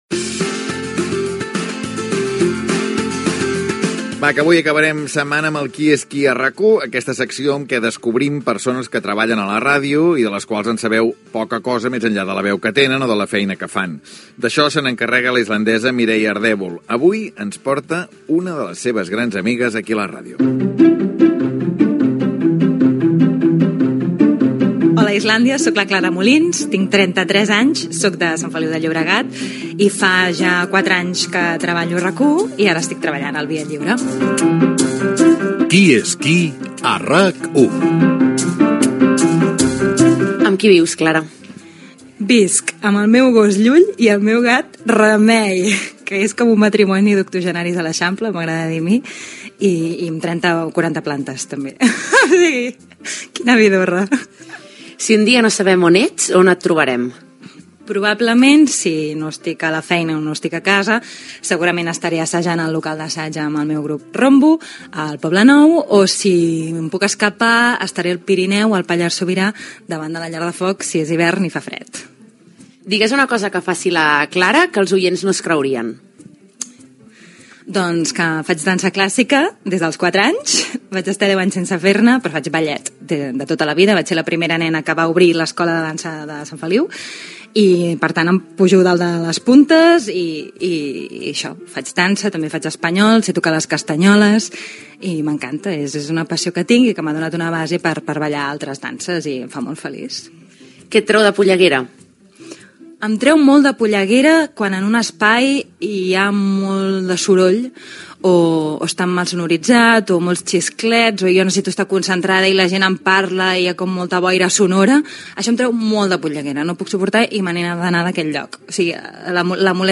amb una entrevista